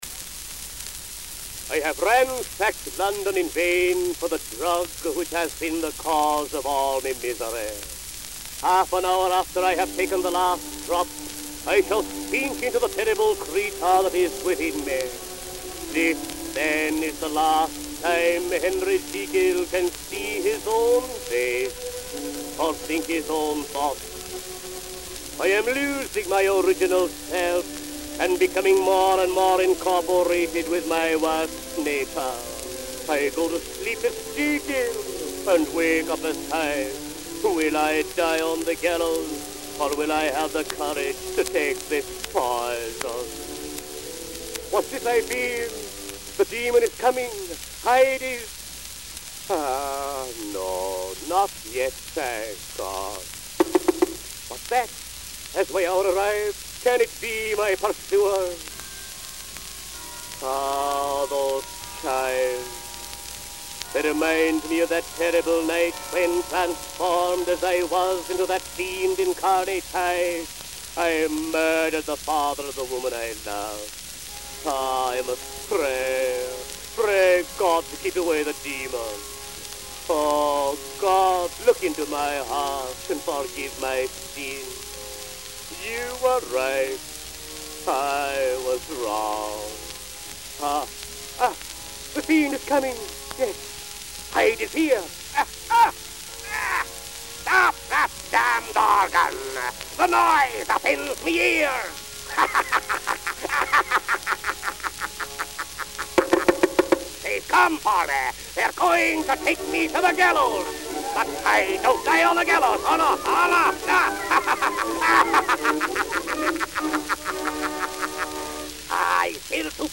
Len Spencer, performer. Columbia matrix, [1904] 1908.